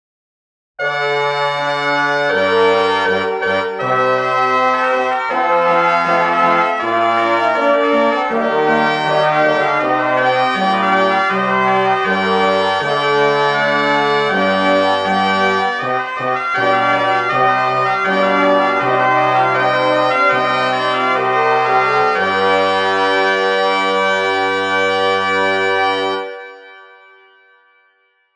G.Gabrieri　8声(mp3)
音源は、比較のために、全て金管にしています。